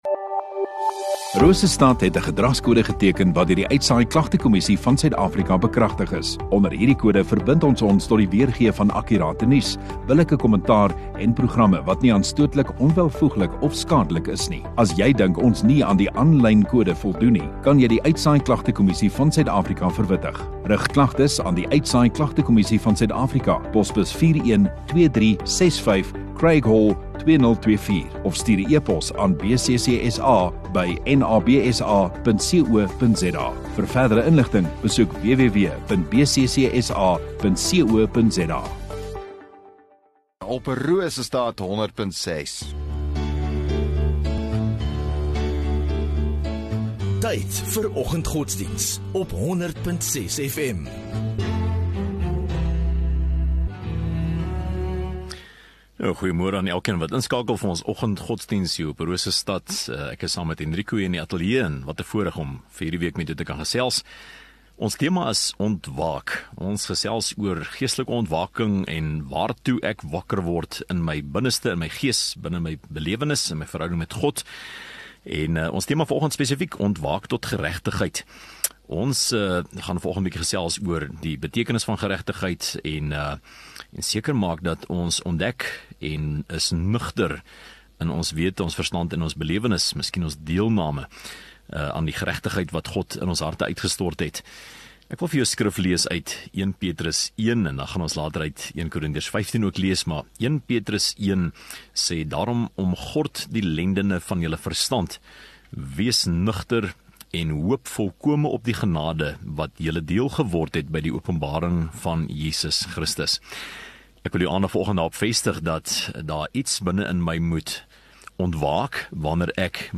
12 Aug Dinsdag Oggenddiens